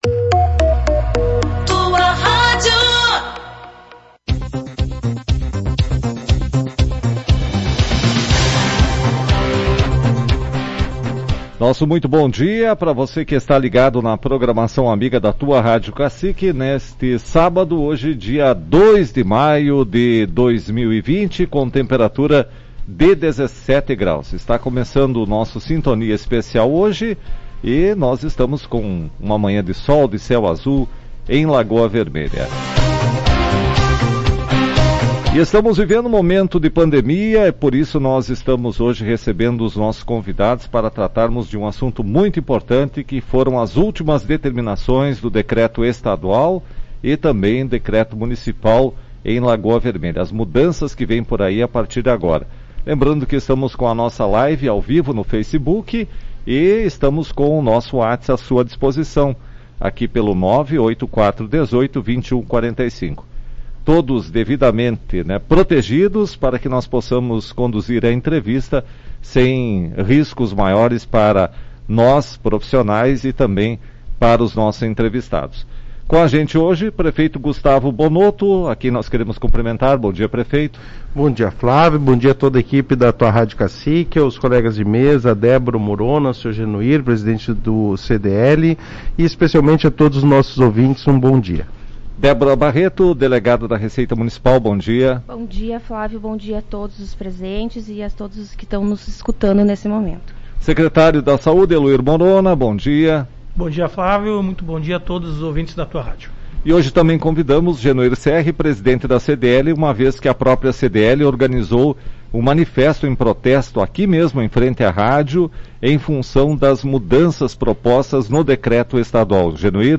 Na manhã deste sábado, durante entrevista do poder executivo de Lagoa Vermelha, na Tua Rádio Cacique, o comércio local, através da Câmara de Dirigentes Lojistas – CDL realiza uma manifestação de repúdio ao fechamento do comércio.
Diversos associados, em frente às instalações da emissora, na Rua 14 de Julho e realizaram um buzinaço e manifestação contrária ao fechamento do comércio local.